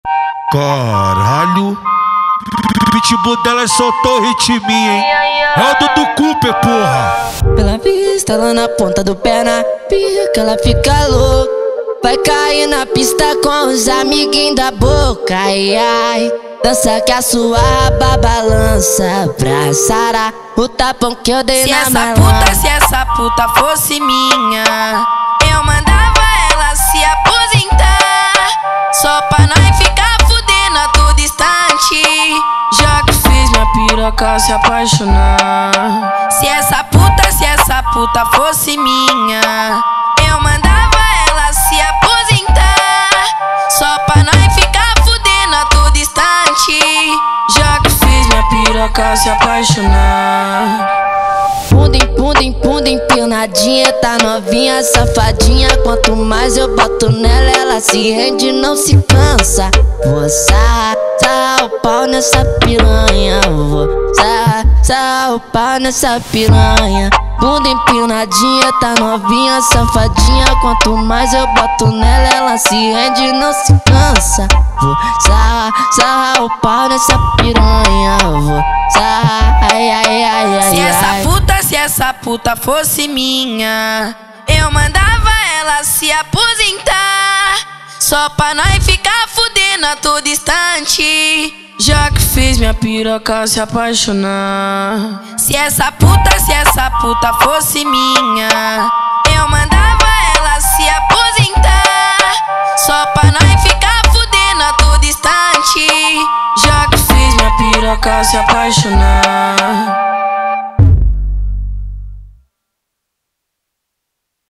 2024-03-31 00:10:23 Gênero: Funk Views